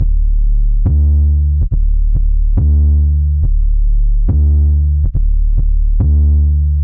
HBS S 嘻哈低音C3 140BPM
Tag: 140 bpm Hip Hop Loops Bass Synth Loops 1.15 MB wav Key : C